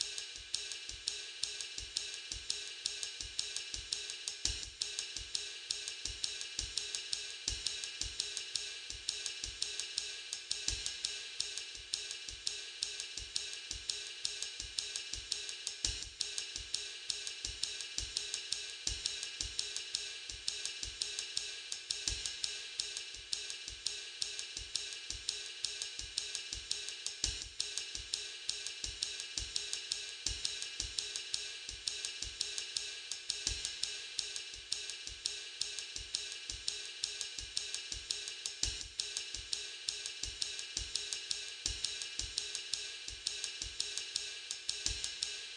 RIDELOOP_16.wav